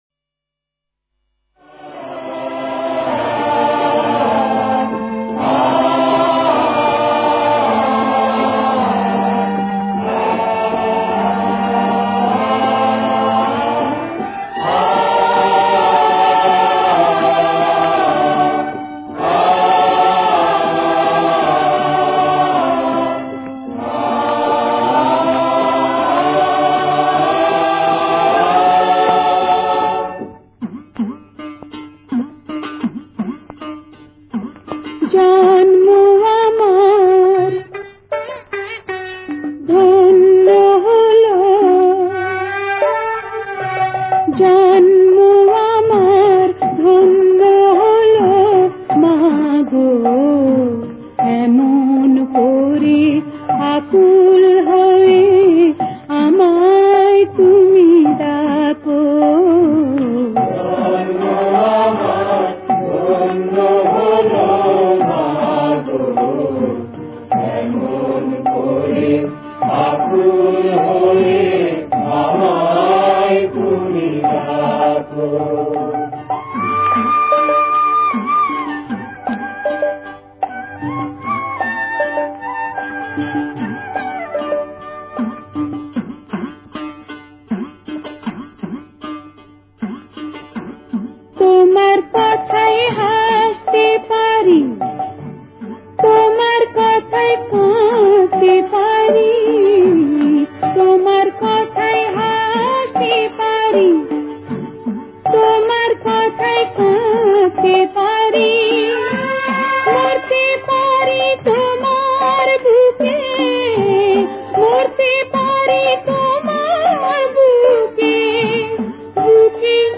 Patriotic Songs